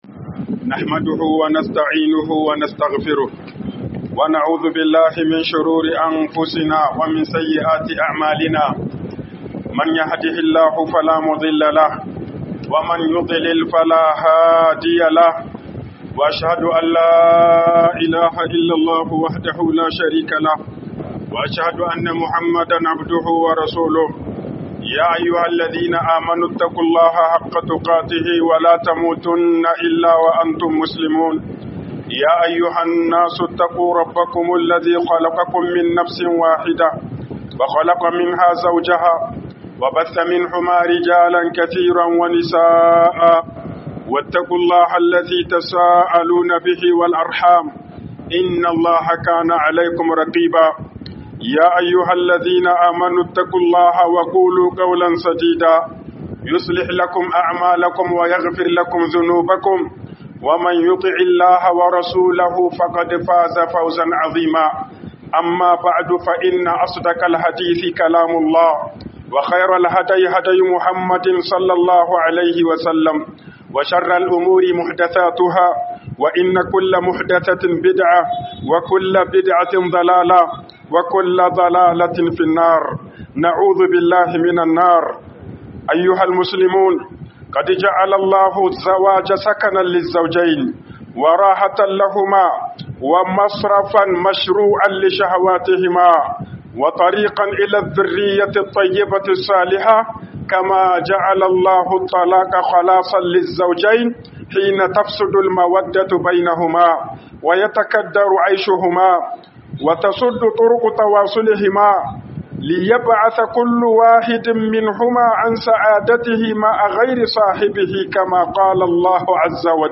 Dalilan Sakin Aure A ƁANGAREN MAZA 01 - HUƊUBOBIN JUMA'A